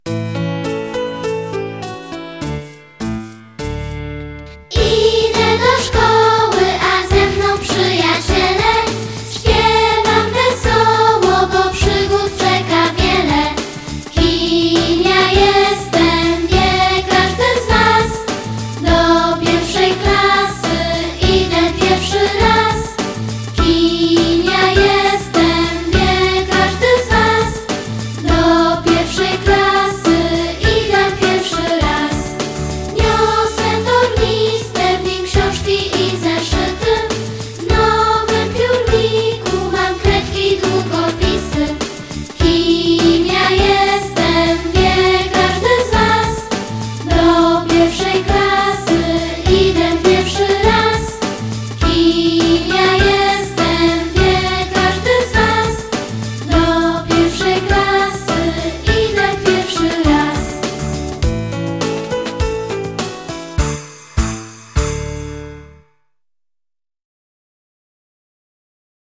Poniżej zamieszczamy tekst utworu oraz link do melodii:
Jestem uczniem - melodia ze śpiewem1 (1).mp3